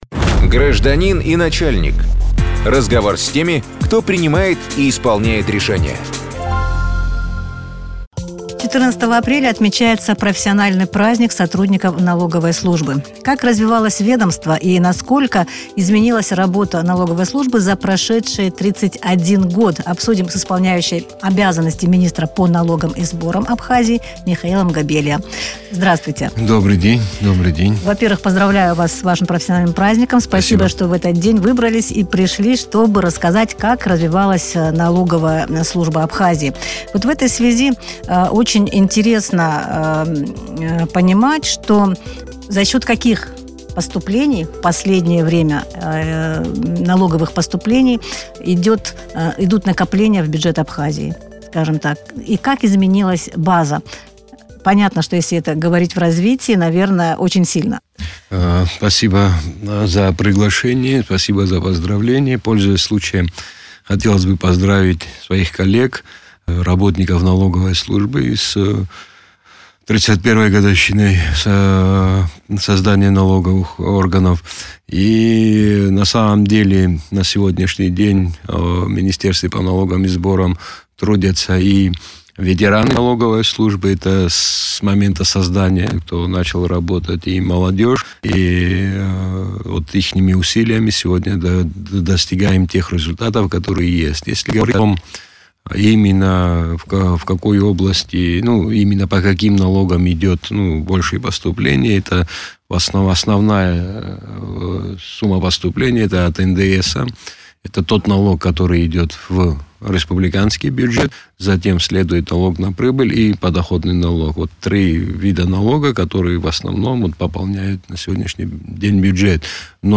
14 апреля профессиональный праздник отметили сотрудники налоговой службы Абхазии. Как развивалось ведомство в течение 31 года, в эфире радио Sputnik рассказал и. о. министра по налогам и сборам Абхазии Михаил Габелия.